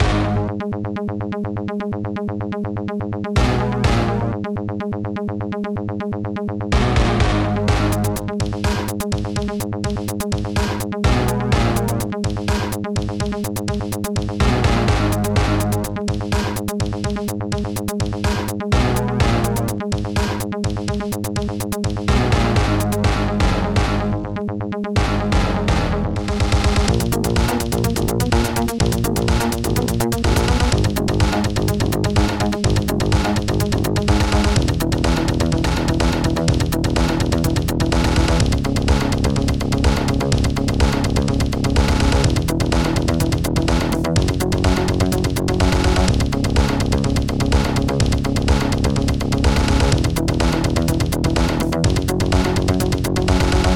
Protracker Module
Instruments analogstring digdug zip3 worksnare aabass2 xbass hiclose deepbass